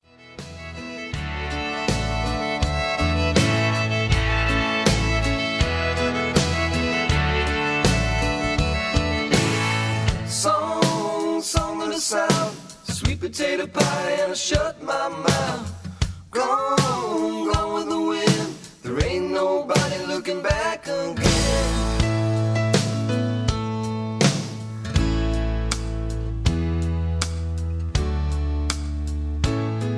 backing tracks, karaoke, sound tracks, studio tracks, rock